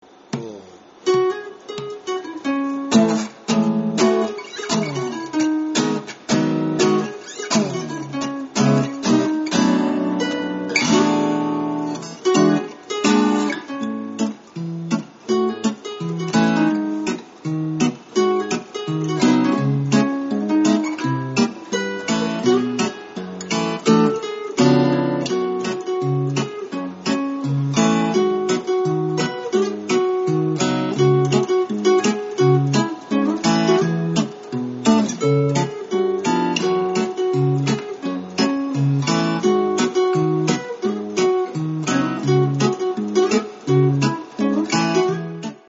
（作曲＆伴奏）
（リードギター）の二人組。